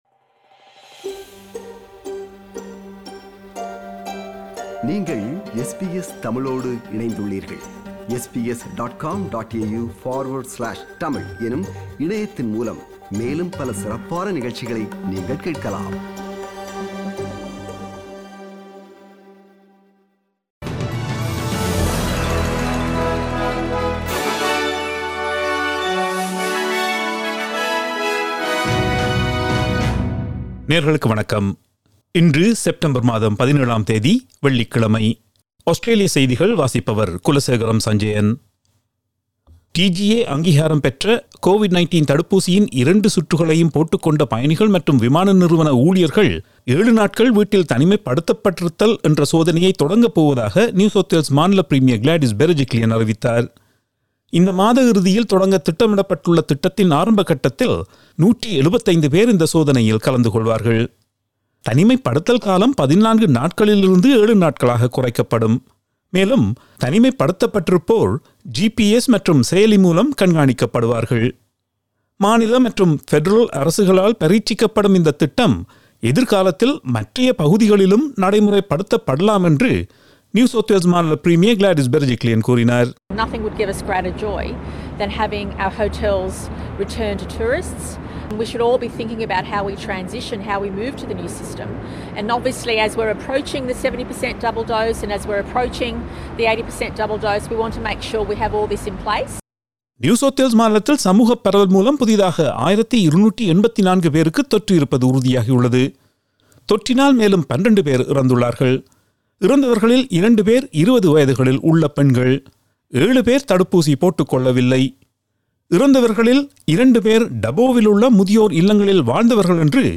SBS தமிழ் ஒலிபரப்பின் இன்றைய (வெள்ளிக்கிழமை 17/09/2021) ஆஸ்திரேலியா குறித்த செய்திகள்.